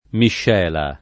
miscela [ mišš $ la ] s. f.